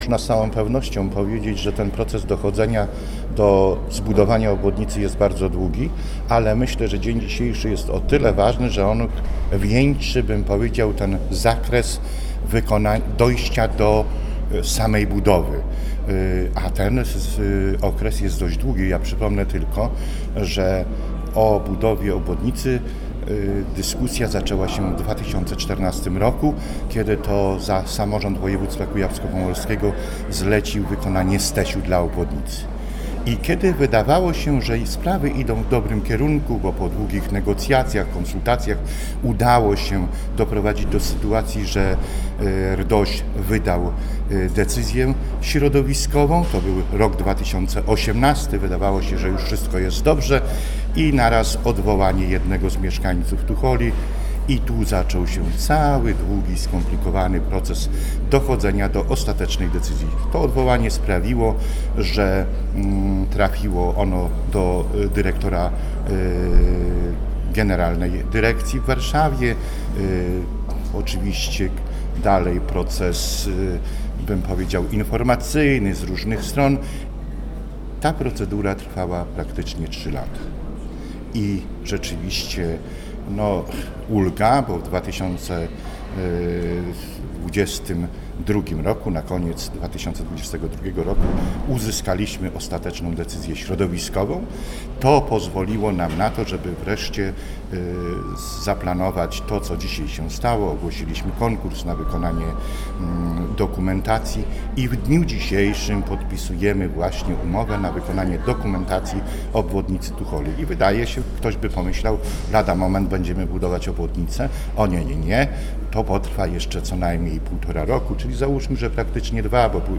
Mówi wicemarszałek Zbigniew Sosnowski.
wicemarszalek-Zbigniew-Sosnowski-obwodnica-Tucholi.mp3